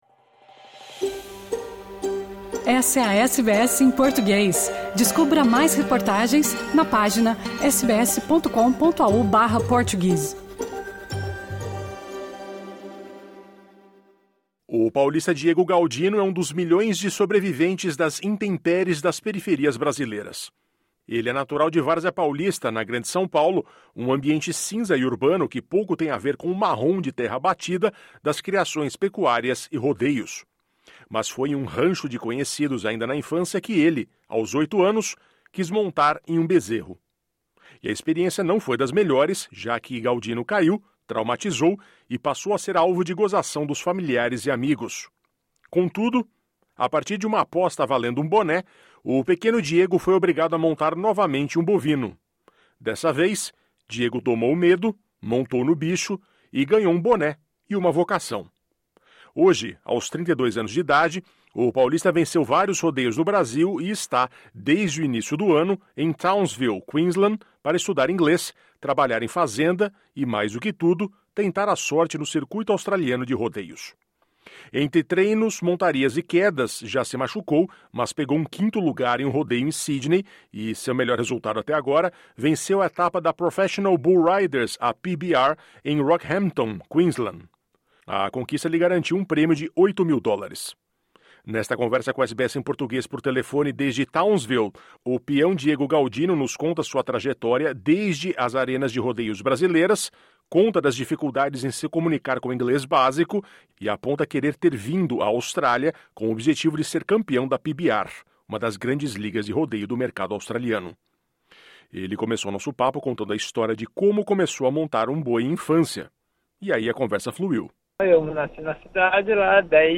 Nesta conversa com a SBS em Português, ele afirma sonhar em ser o primeiro brasileiro a ser campeão da modalidade no país.